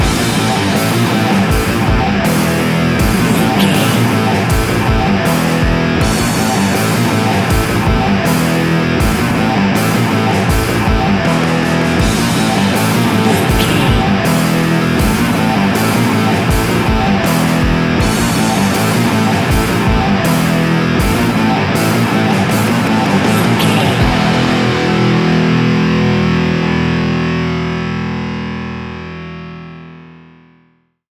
Ionian/Major
hard rock
heavy rock
distortion